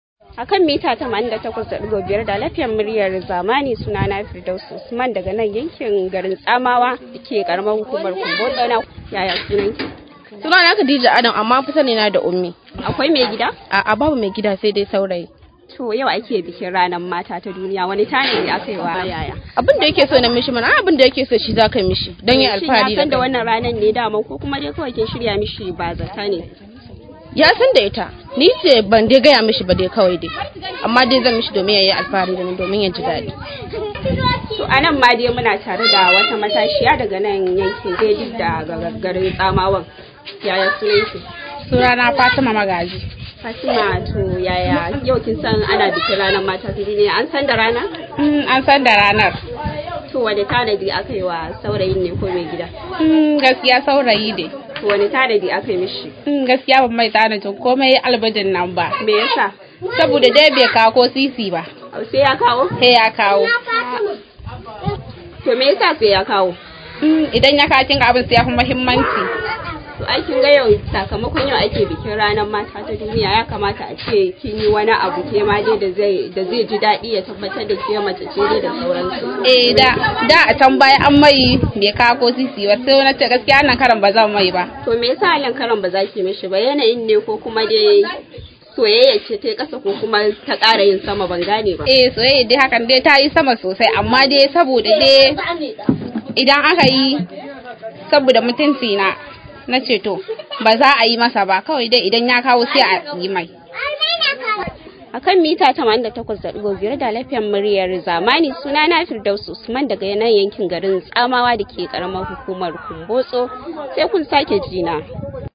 Rahoto: Ban yi wa saurayi na tanadi ba a ranar Mata ta duniya saboda bai kawo ko sisi ba